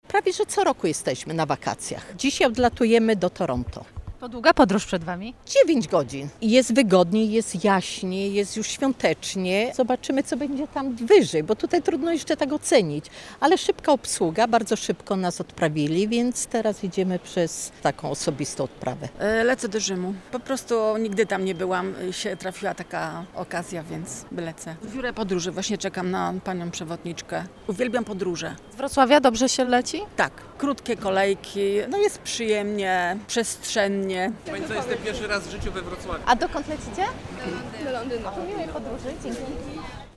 – Krótkie kolejki, jest przyjemnie. Z Wrocławia dobrze się lata – mówią o wrażeniach pasażerowie lotniska.
sonda_lotnisko.mp3